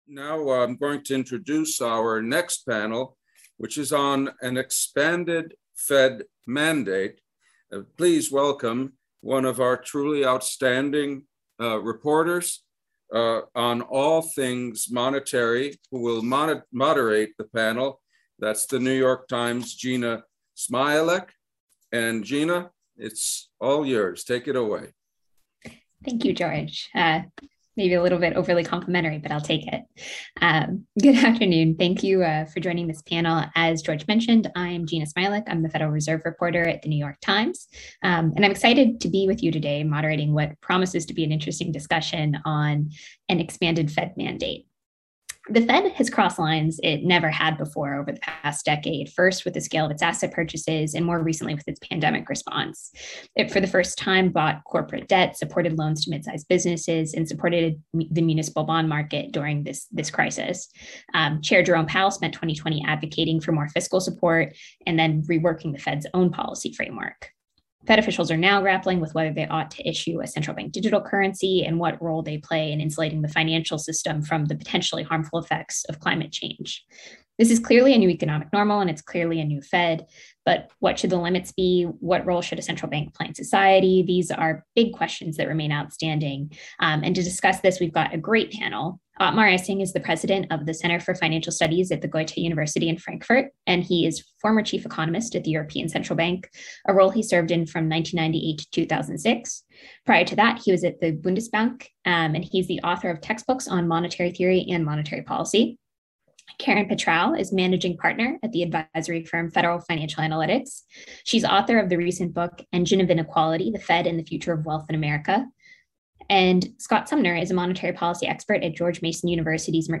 39th Annual Monetary Conference: Panel 3: An Expanded Fed Mandate?